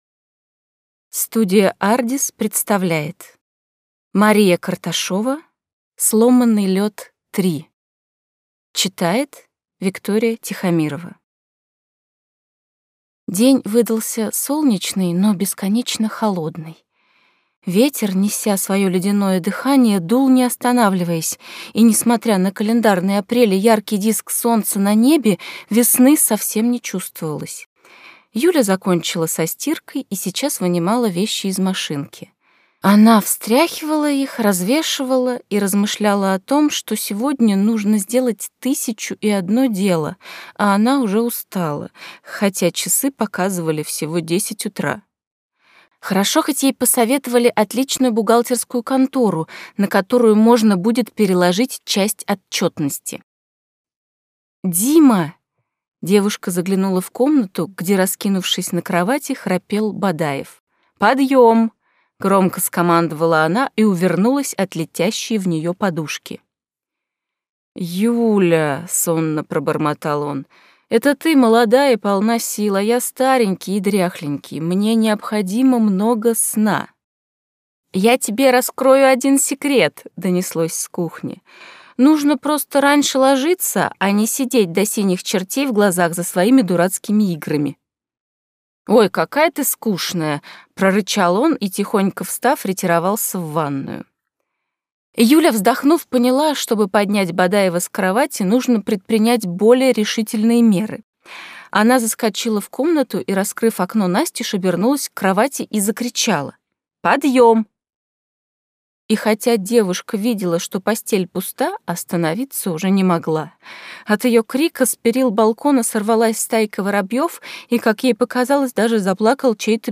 Аудиокнига Сломанный лёд – 3 | Библиотека аудиокниг